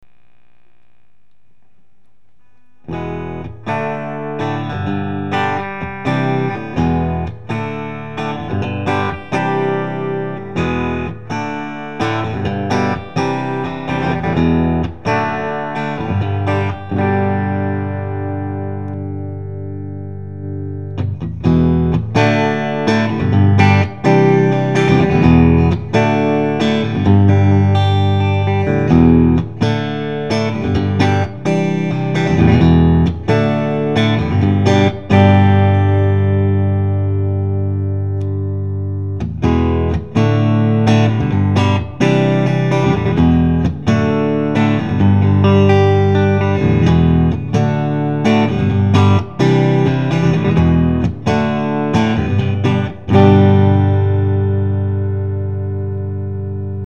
mine de rien j'ai entendu des samples d'un mec qui a acheté une telecaster chez Ishibashi je vous file les liens vers les samples ca arrache tout